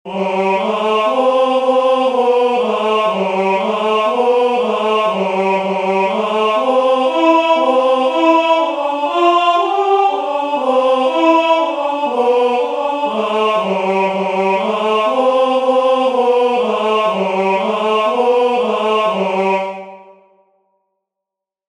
"Exultet orbis gaudiis," a hymn, apparently from the office of Vespers, Common of Apostles